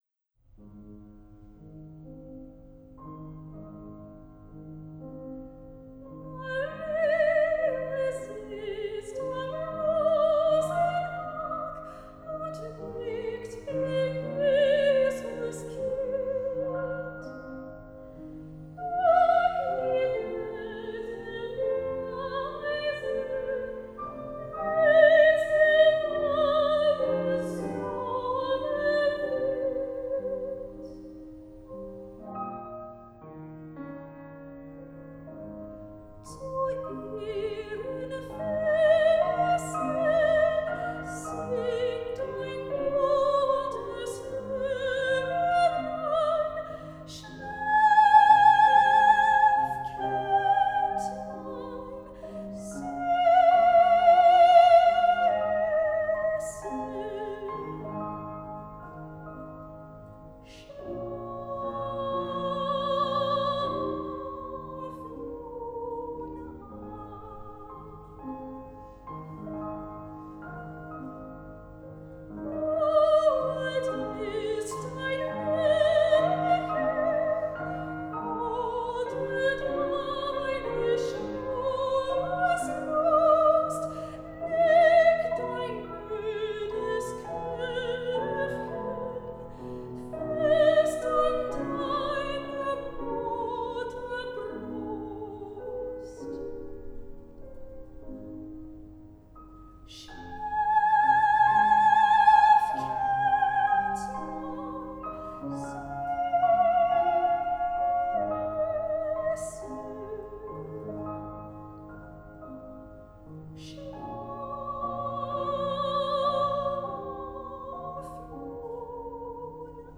soprano
Solo song:
piano